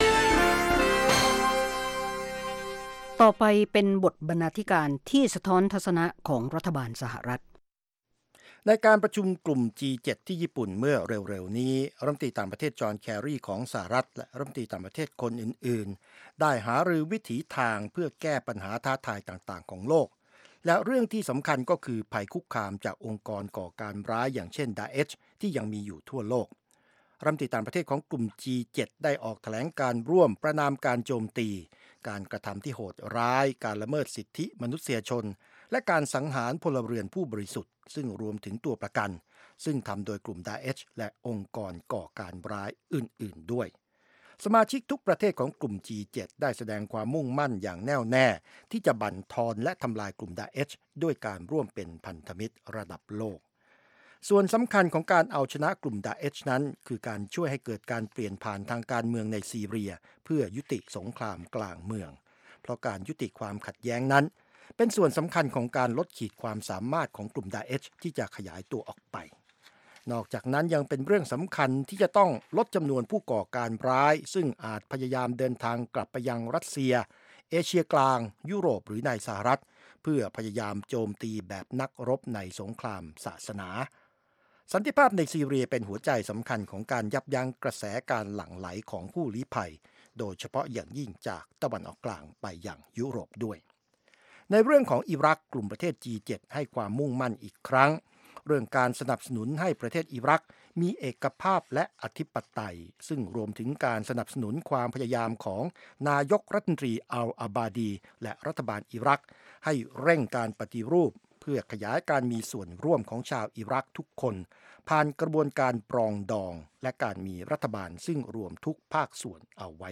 วาไรตี้โชว์ ฟังสบายๆ สำหรับวันหยุดสุดสัปดาห์ เริ่มด้วยการประมวลข่าวในรอบสัปดาห์ รายงานเกี่ยวกับชุมชนไทยในอเมริกา หรือเรื่องน่ารู้ต่างๆ ส่งท้ายด้วยรายการบันเทิง วิจารณ์ภาพยนตร์และเพลง